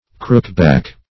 Crookback \Crook"back`\ (kr[oo^]k"b[a^]k`), n.